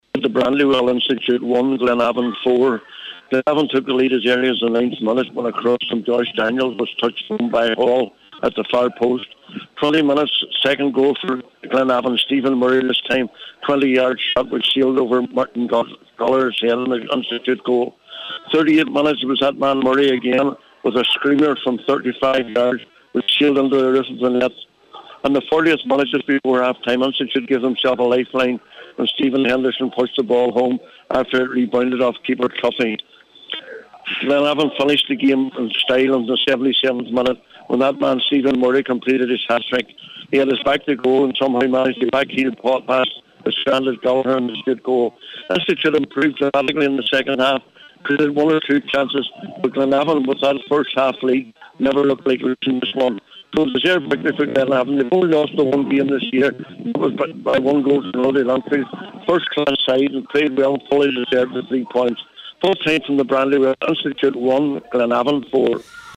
reports for Highland Radio Sport…